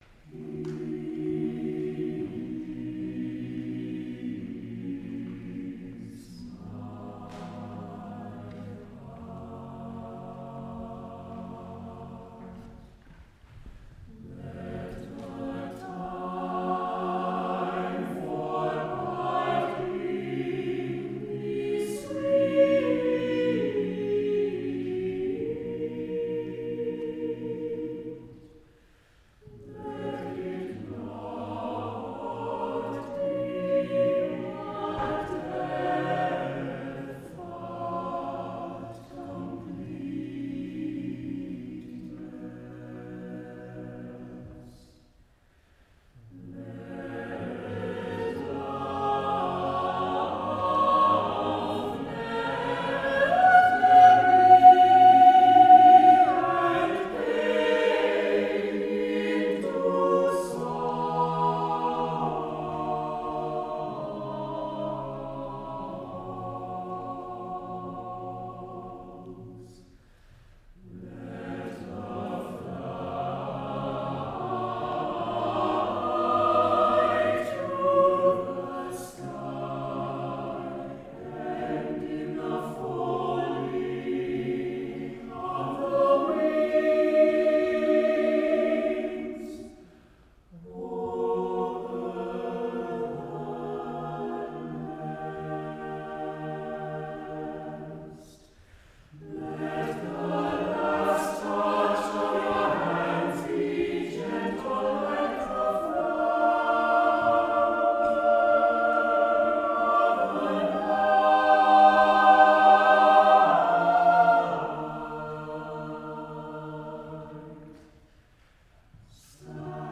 lawson gould choral